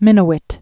(mĭnə-wĭt), Peter